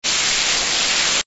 firehose_spray.ogg